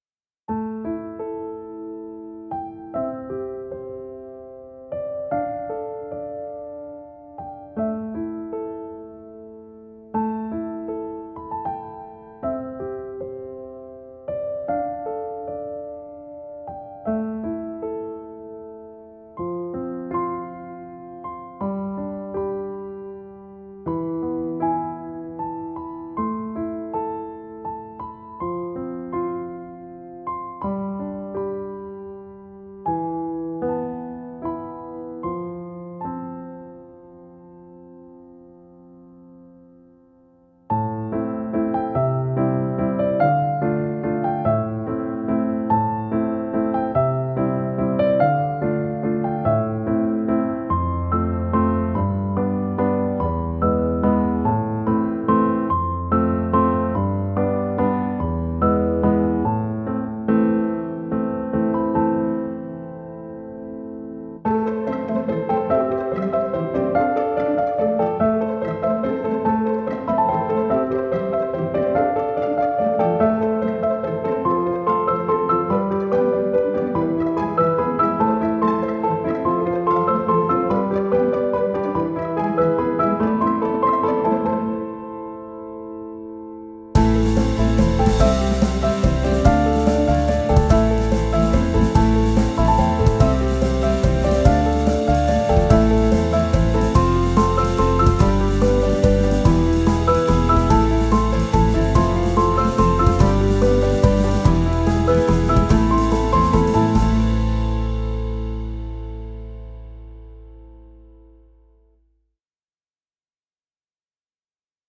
tema dizi müziği, mutlu rahatlatıcı neşeli fon müziği.